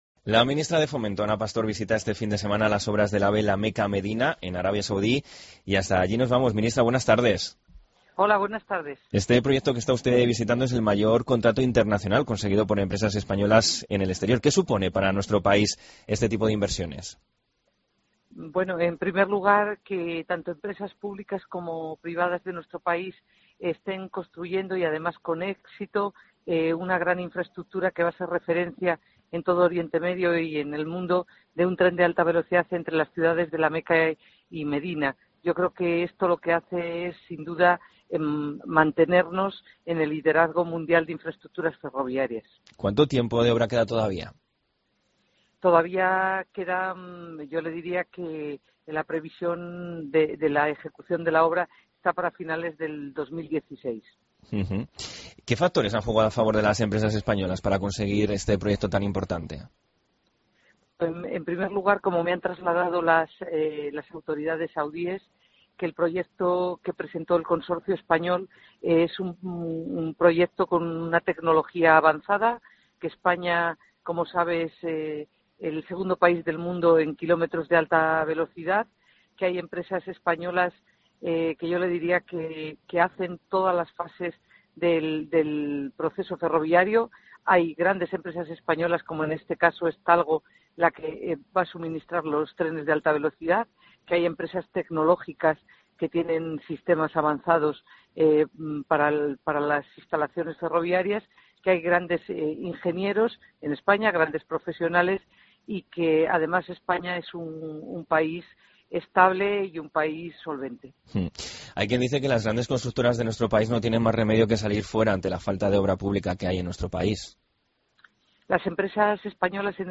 Entrevista a Ana Pastor en Mediodía COPE sobre el AVE-La Meca